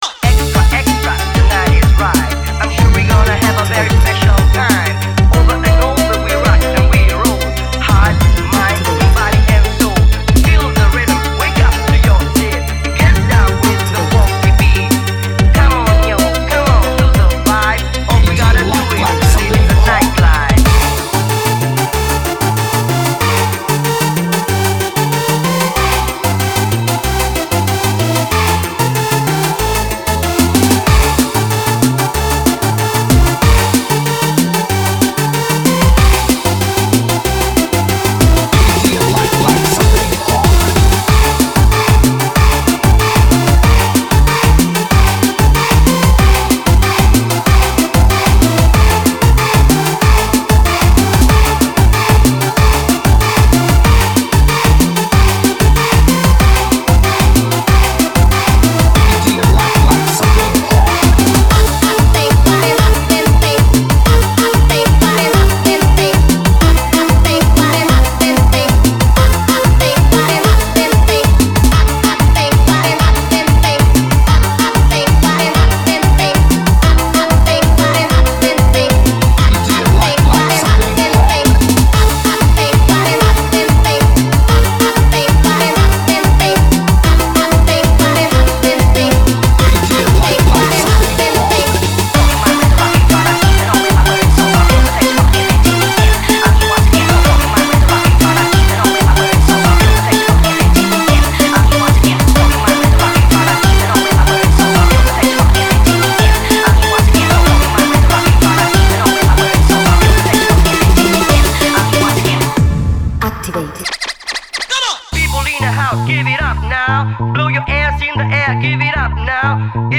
音重磅真空登陆，点燃全球各大夜店派对的潮流摇摆音浪，华丽震撼的律动节奏，全球夜场最热DJ舞曲
，最HIGH-慢摇-劲爆舞曲。
娇艳的鼓点，炽热的节奏让空气中也充满性感，让你无法抵挡，动感串烧舞曲的魅力